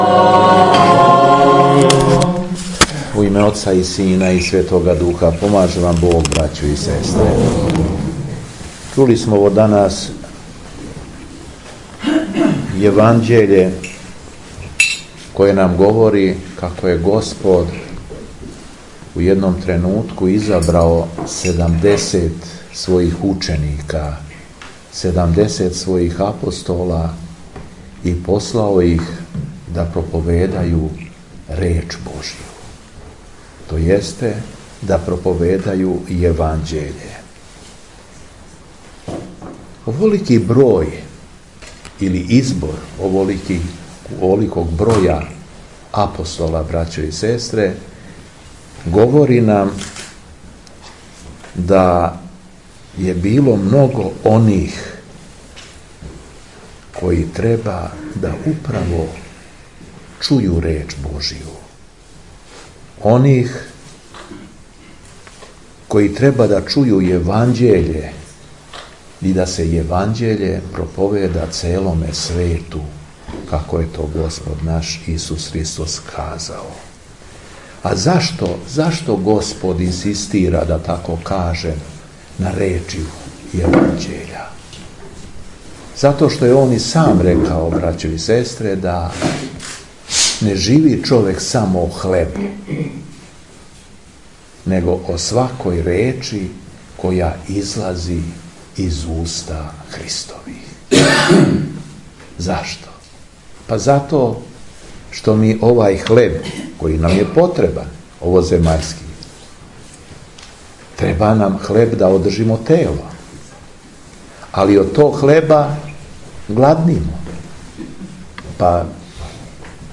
У уторак 4. фебруара 2020. године, на празник светог апостола Тимотеја и светог преподобномученика Анастасија, Његово Преосвештенство Епископ шумадијски Г. Јован служио је свету архијерејску Литургију у манастиру Ралетинац.